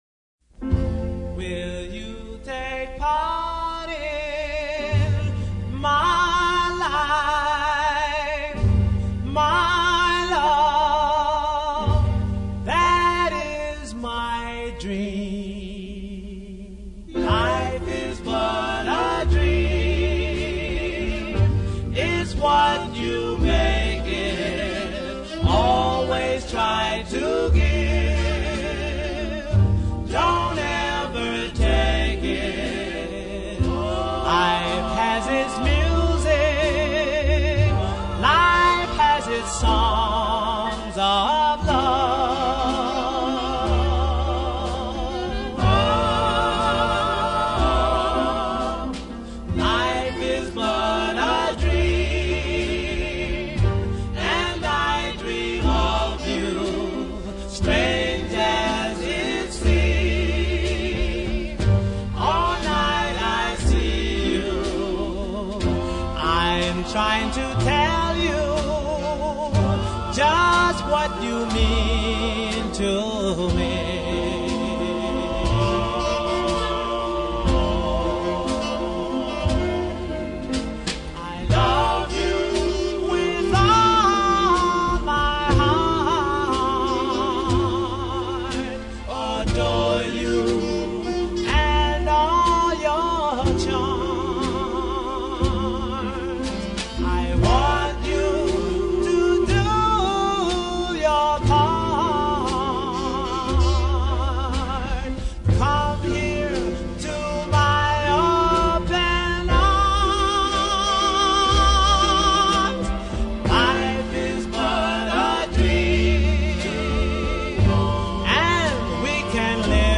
and very appropriate Doo Wop song